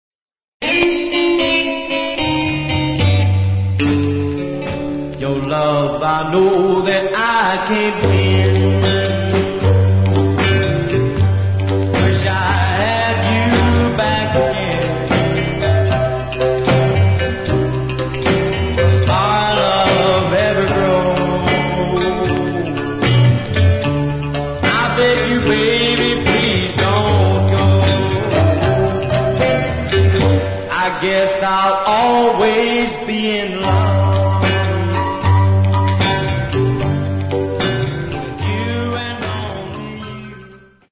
US Rock 'n' Roll singer
Talents : Vocals
Style musical : Rock 'n' Roll, Doo Wop